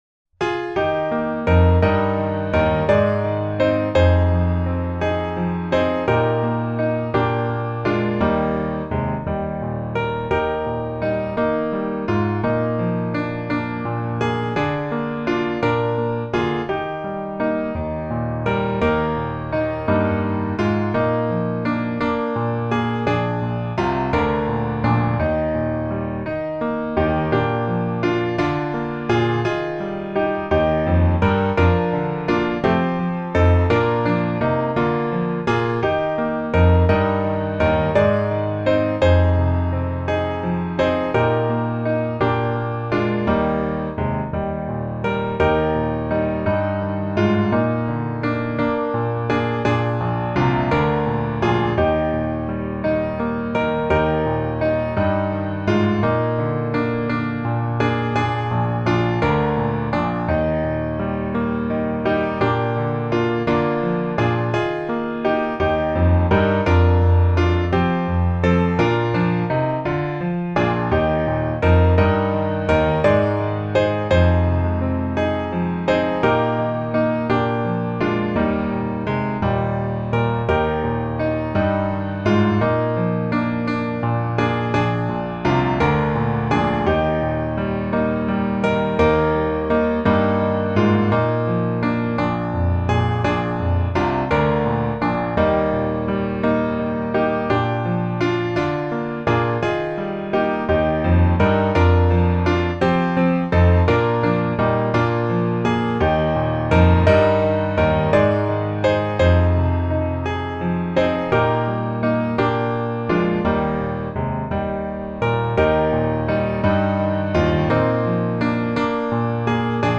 降E大調